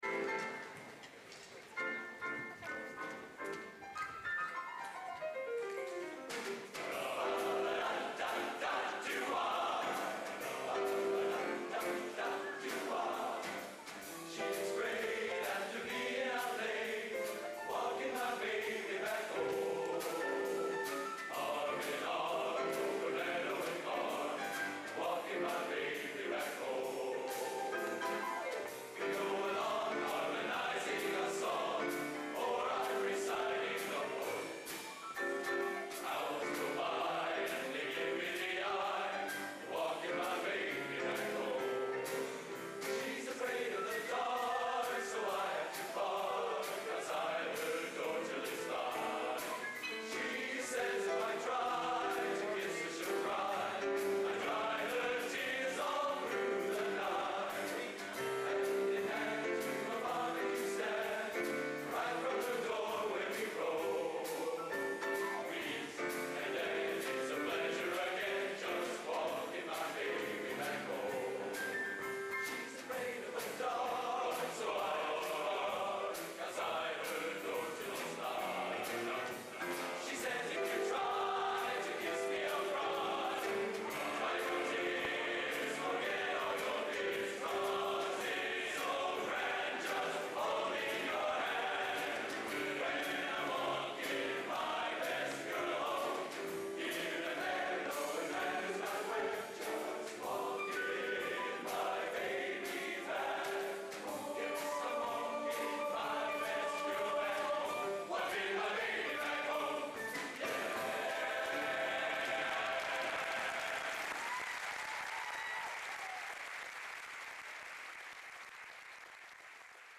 Location: Purdue Memorial Union, West Lafayette, Indiana
Genre: Popular / Standards Schmalz | Type: